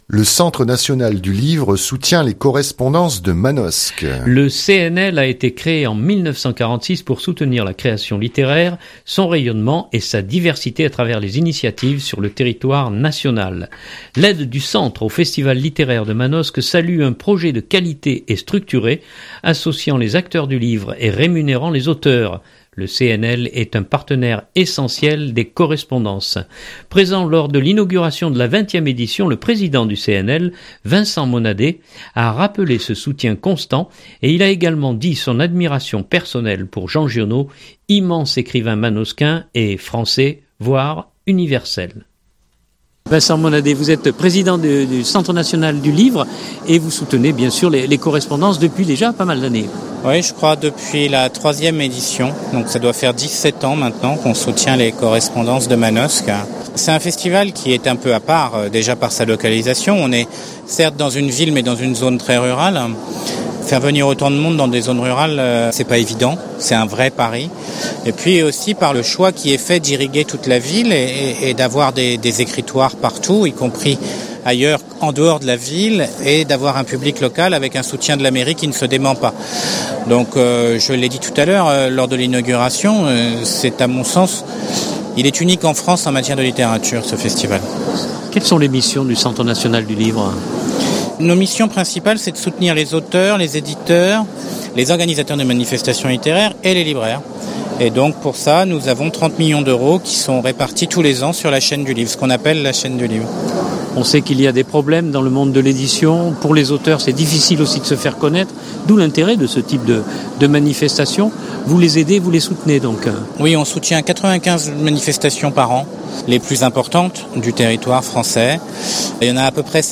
Présent lors de l’inauguration de la 20ème édition, le président du CNL Vincent Monadé a rappelé ce soutien constant et il a également dit son admiration personnelle pour Jean Giono, immense écrivain manosquin et… français, voire universel !